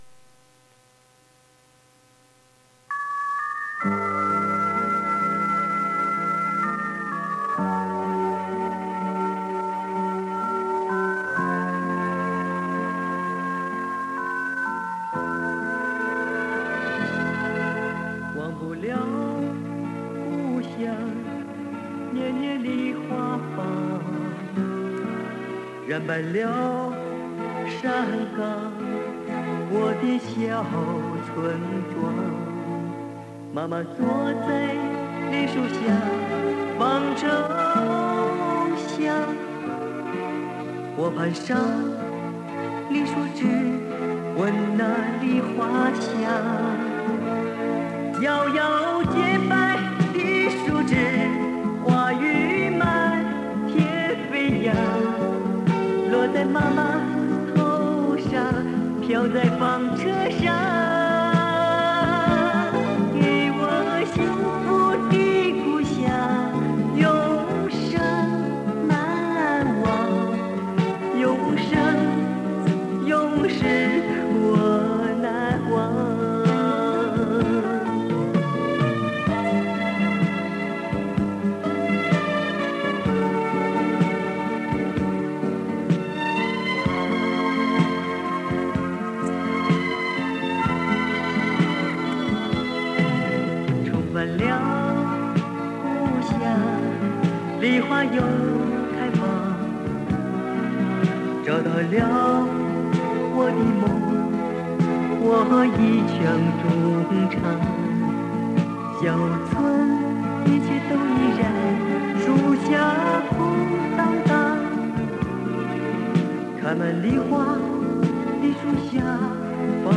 电子音效和动感节奏的配器在当时国内无疑极具先锋和前卫意义